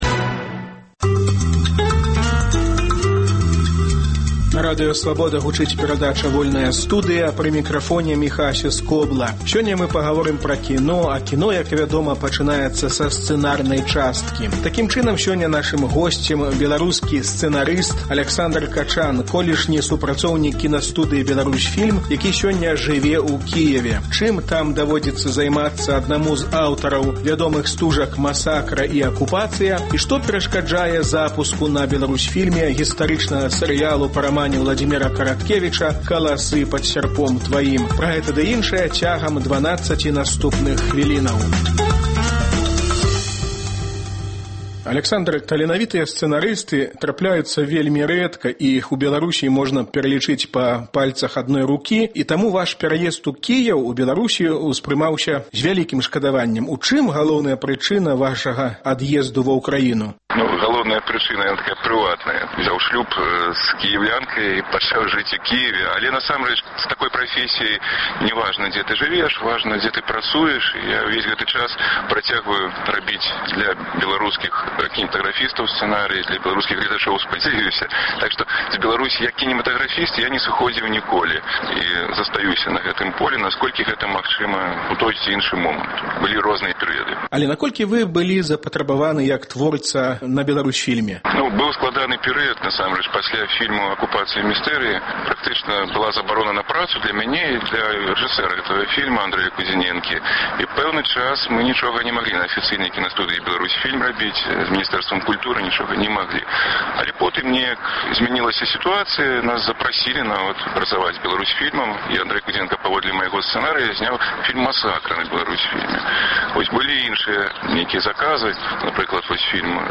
Што адбываецца ў беларускім кінематографе? Што перашкаджае запуску ў вытворчасьць на "Беларусьфільме" стужкі "Каласы пад сярпом тваім"? Пра гэта і пра іншае ў гутарцы